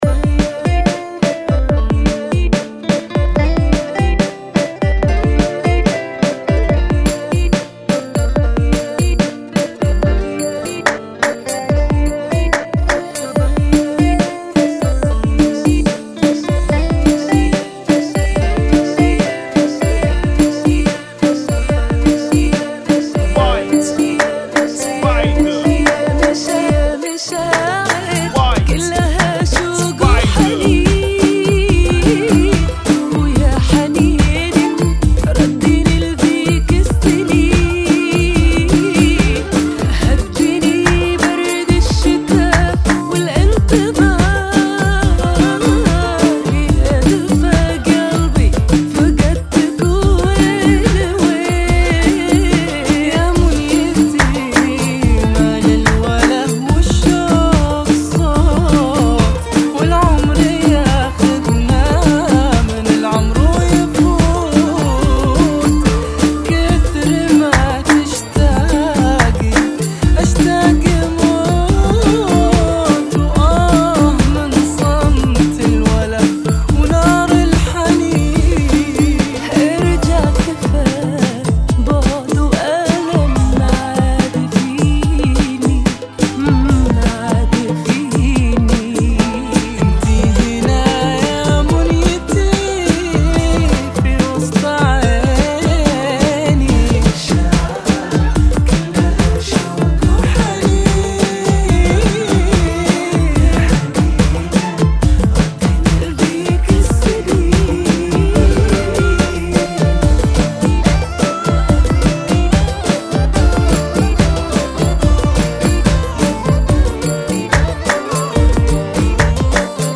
Funky [ 72 Bpm ]